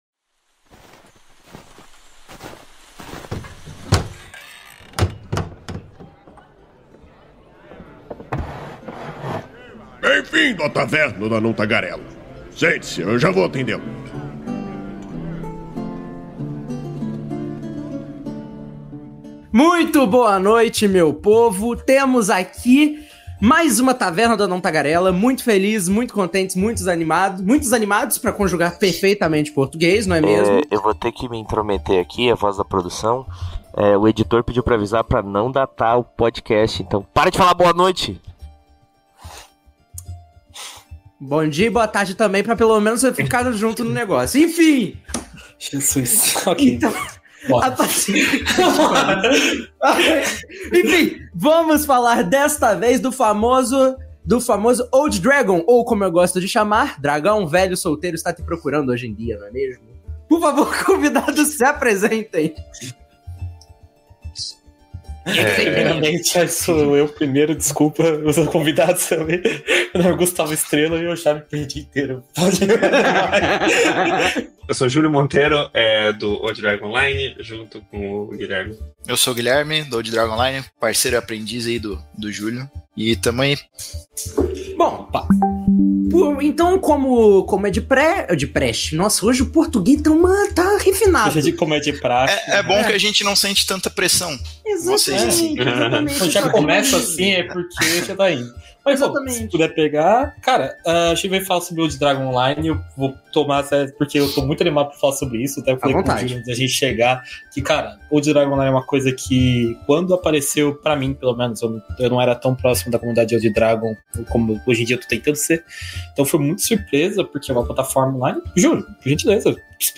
Venha entender melhor sobre esse serviço de organizações de fichas e campanhas para Old Dragon 2ª Edição, saber mais sobre a evolução da plataforma e os planos para seu futuro. A Taverna do Anão Tagarela é uma iniciativa do site Movimento RPG, que vai ao ar ao vivo na Twitch toda a segunda-feira e posteriormente é convertida em Podcast.